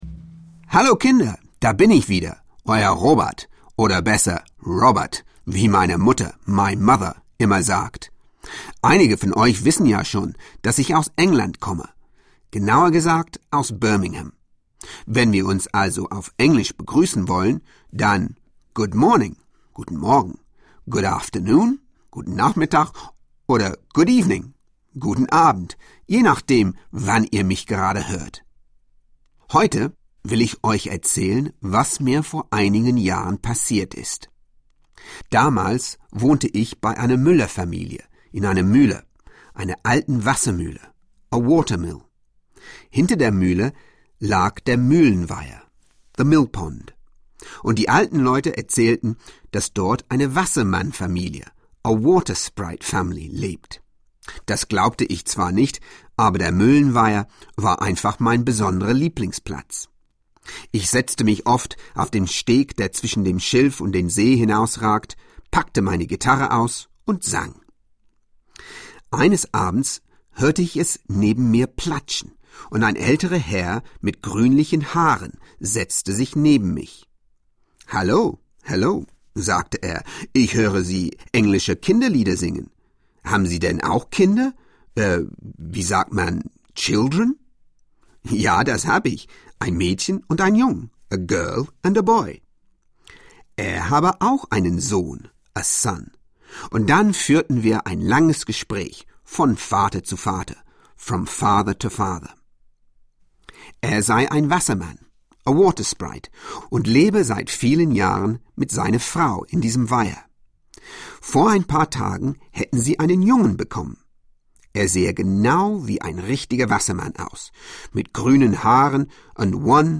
Sprach-Hörspiel für Kinder nach Motiven des gleichnamigen Kinderbuchs von Otfried Preussler (1 CD)
Dazu jede Menge Lieder zum Mitsingen!